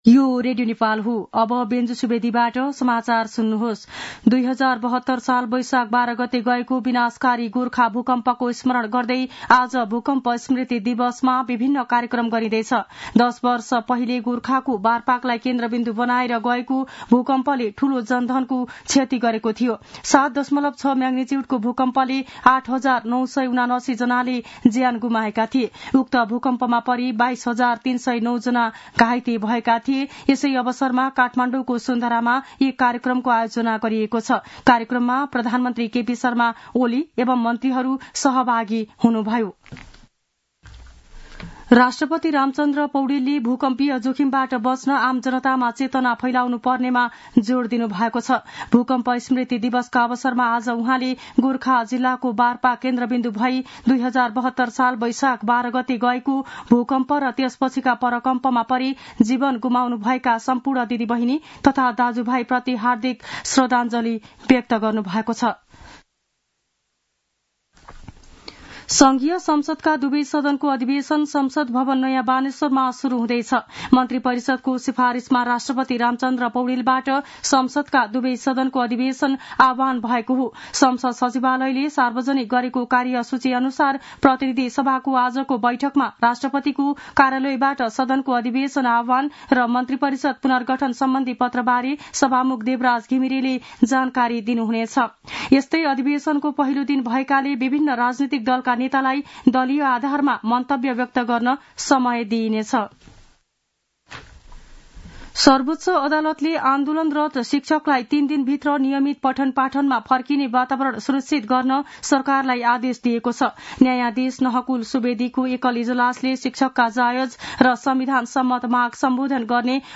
दिउँसो १ बजेको नेपाली समाचार : १२ वैशाख , २०८२